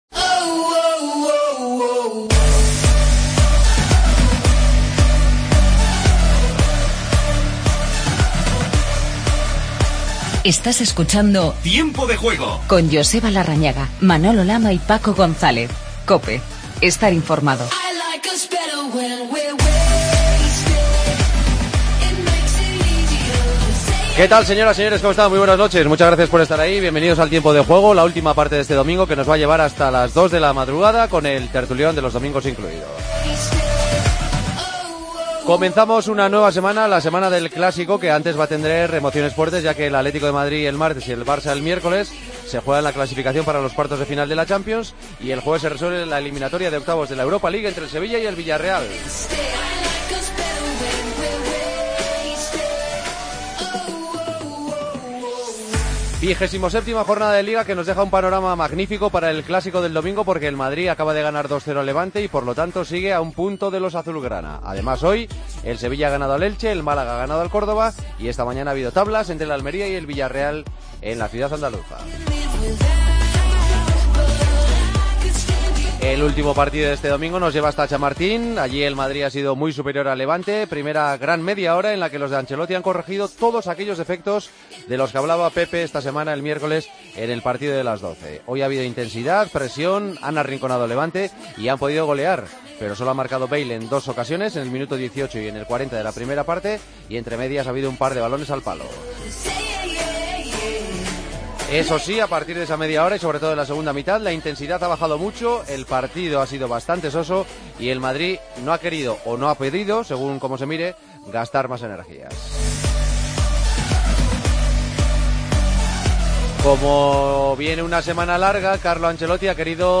El Real Madrid gana al Levante y llegará al Camp Nou con un punto de desventaja. Entrevista a Pepe Mel, tras conseguir el liderato de Segunda con el Betis.